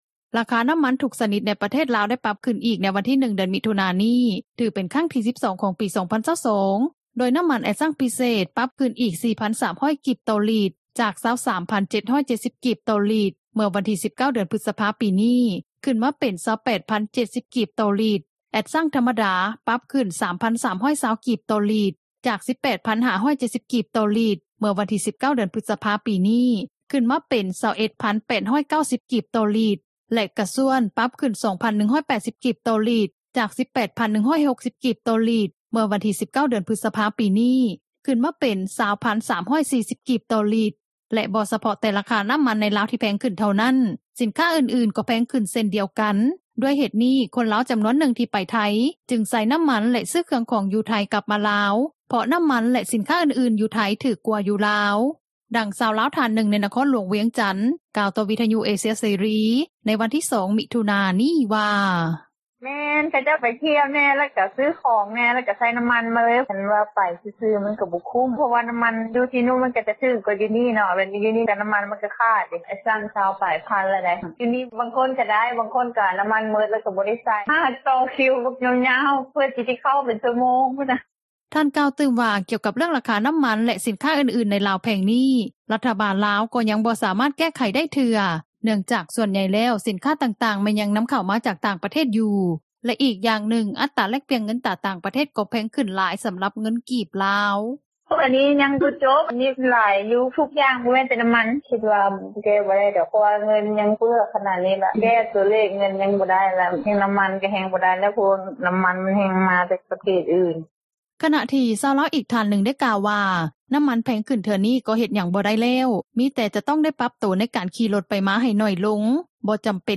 ດັ່ງຊາວລາວ ທ່ານນຶ່ງ ໃນນະຄອນຫຼວງວຽງຈັນ ກ່າວຕໍ່ວິທຍຸເອເຊັຽເສຣີ ໃນວັນທີ່ 2 ມິຖຸນາ ນີ້ວ່າ: